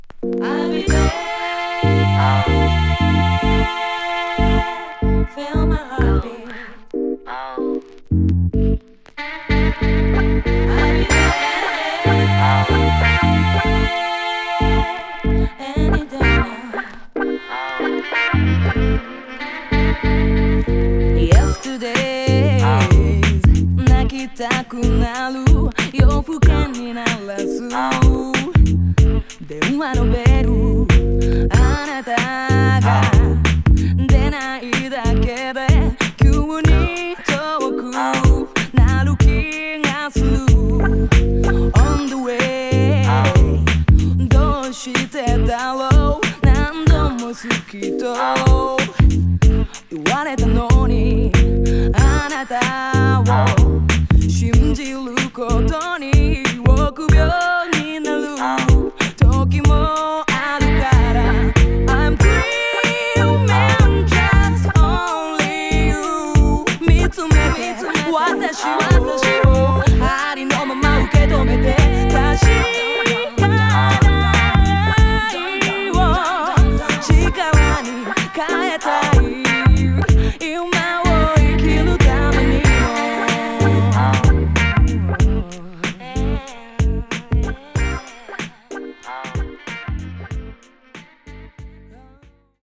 '99のメジャー・デビュー・シングル。